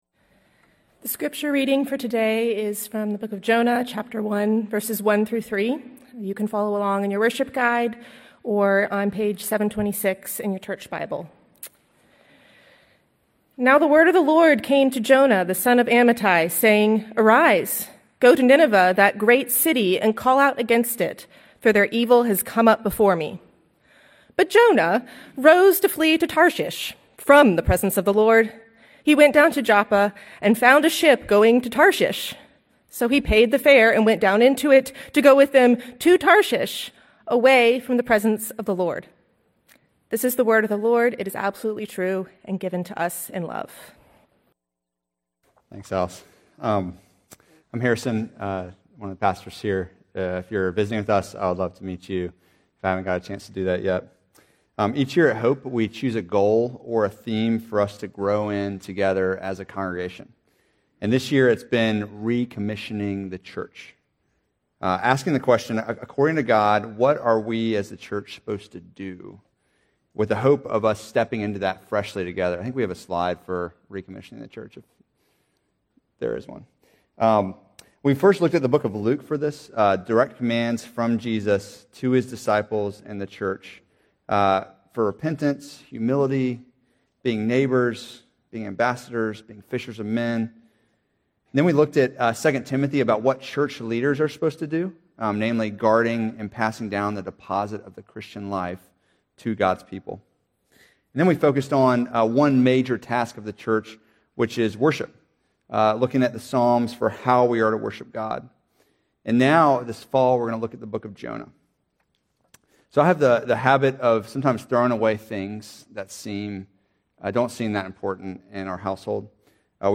Sermons – Hope Chapel